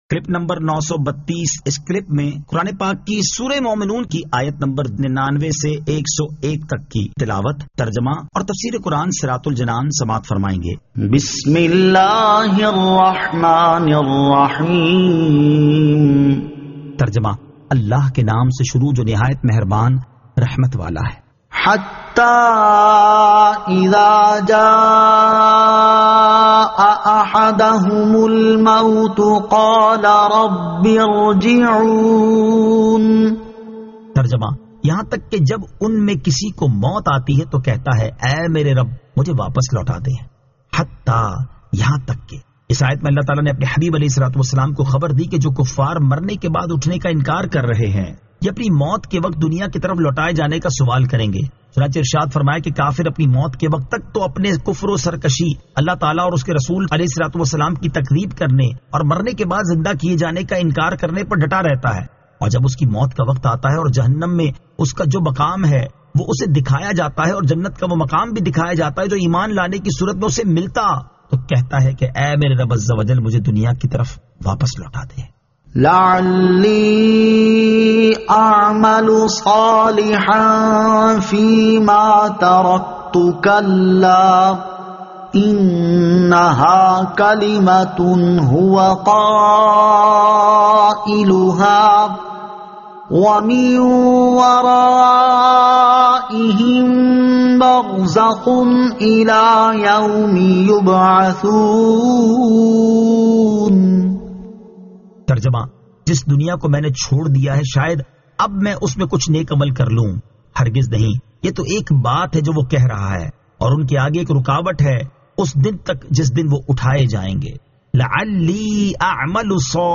Surah Al-Mu'minun 99 To 101 Tilawat , Tarjama , Tafseer